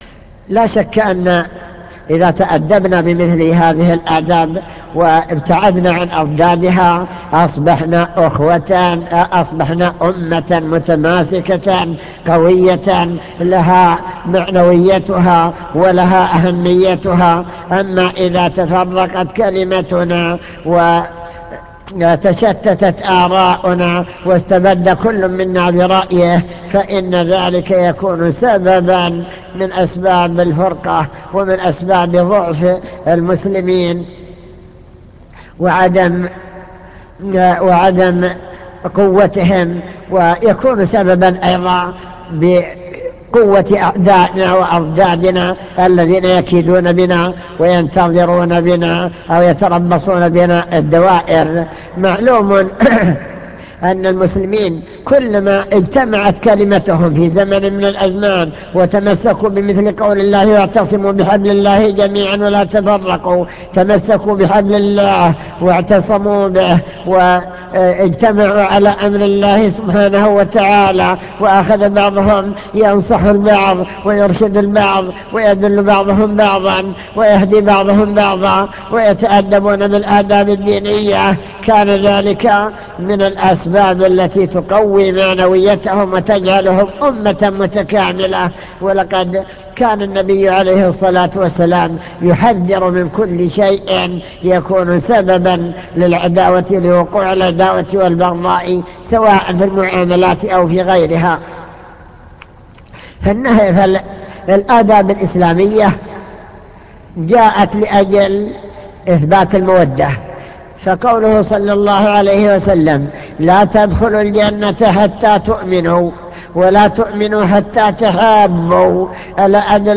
المكتبة الصوتية  تسجيلات - محاضرات ودروس  درس الآداب والأخلاق الشرعية